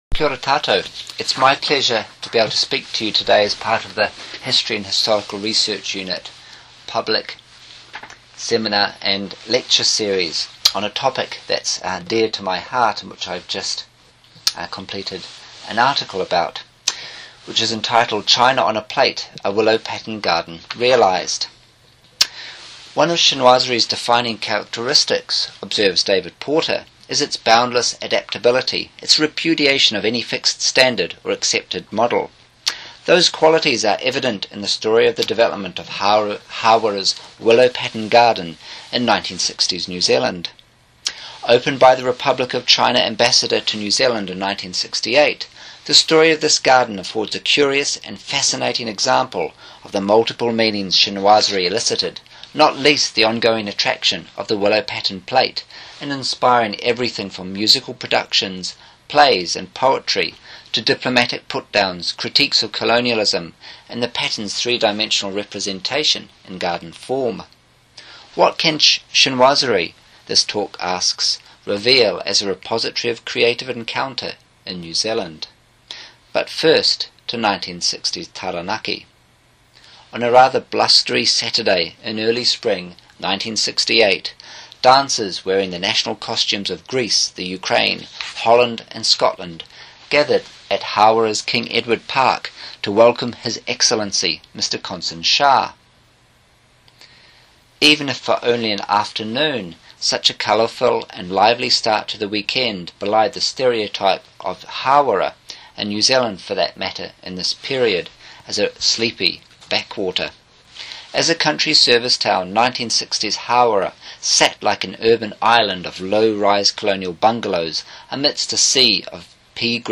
The talk and recording was made as part of the History and Historical Research Unit Public Seminar, 4 June 2015, University of Waikato.